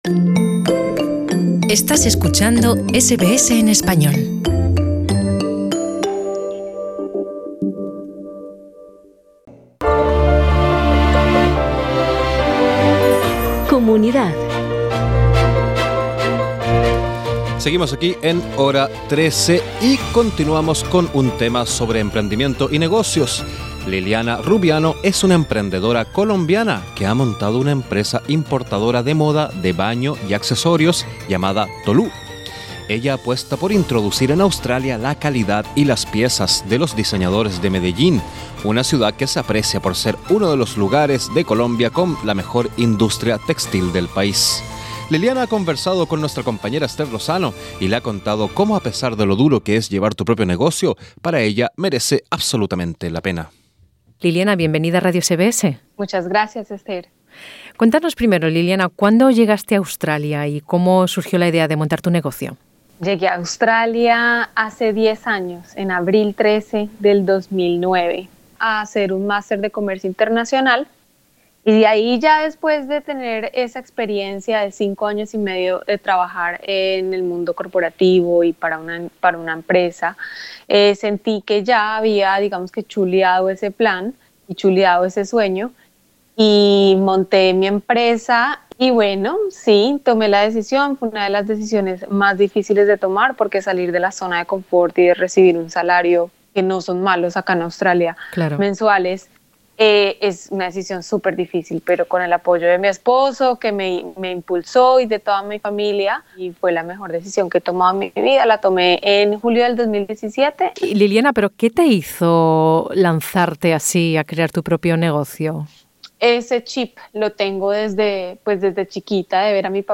Conoce a esta emprendedora colombiana que aterrizó de casualidad en el mundo de la moda persiguiendo el sueño de montar su propia empresa. Escucha la entrevista